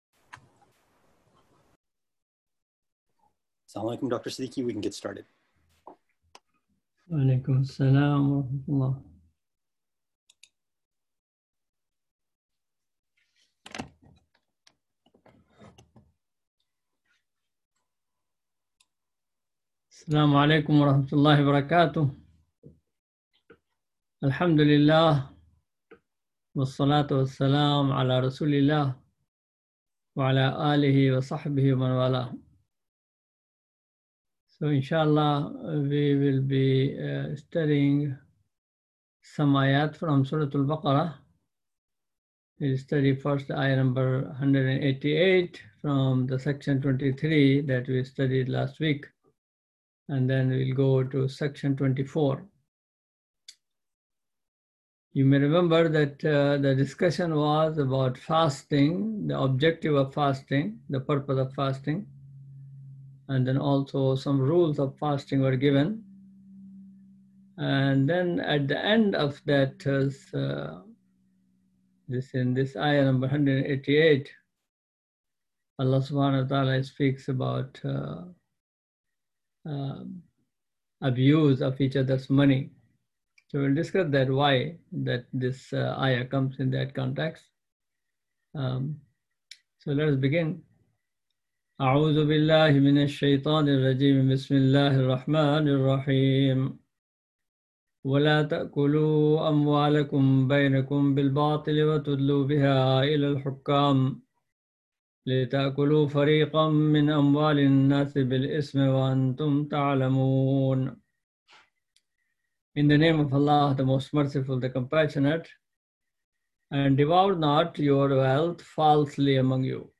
Weekly Tafseer